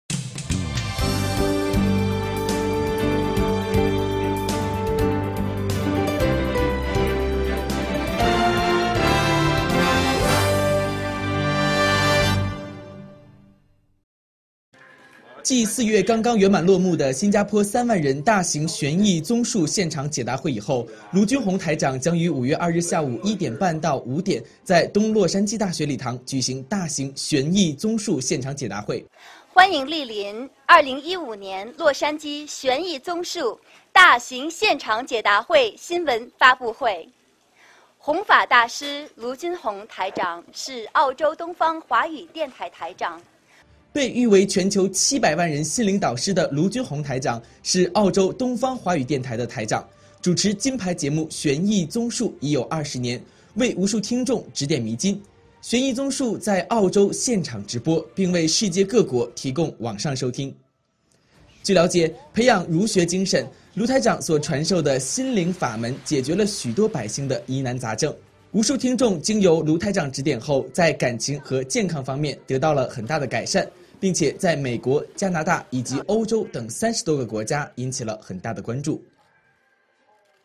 大型现场解答会新闻发布会